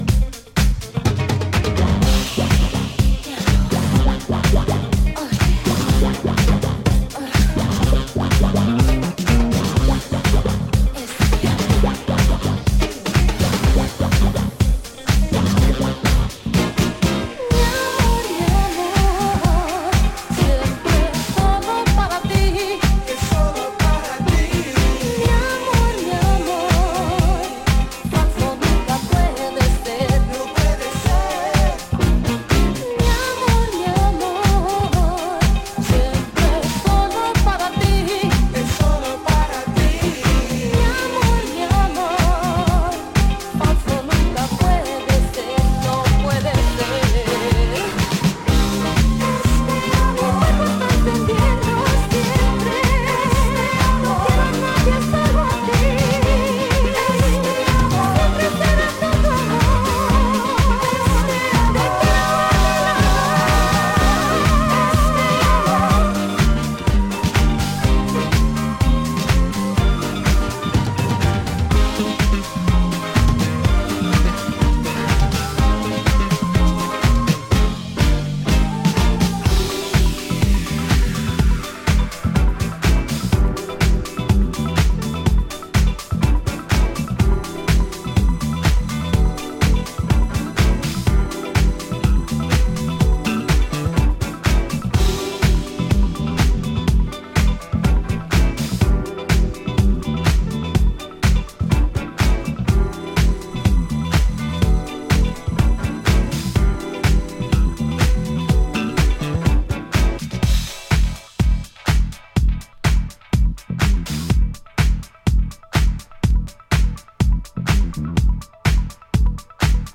distinctly underground energy